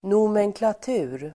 Ladda ner uttalet
Uttal: [nomenklat'u:r]